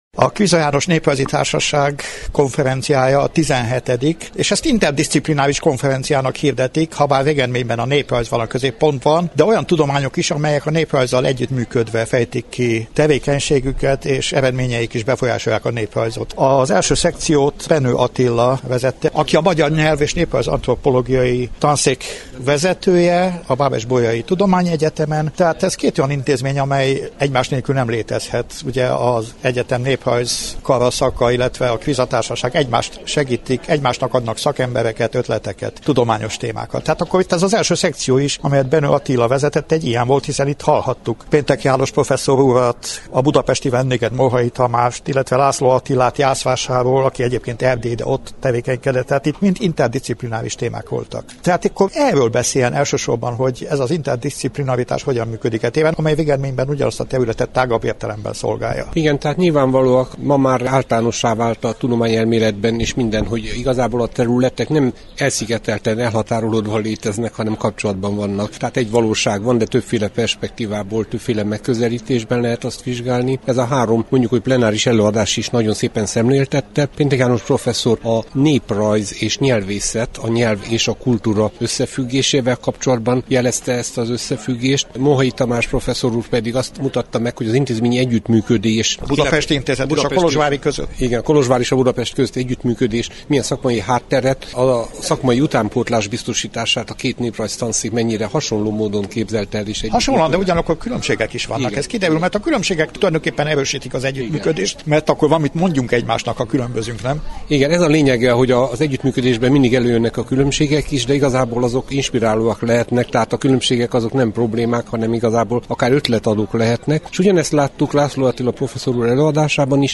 Három interjút hoztunk a nemrég zajlott kutatástörténeti konferenciáról.
Összeállításunkban a munkálatokon részt vett előadókkal és szervezőkkel készített interjúk hangzanak el.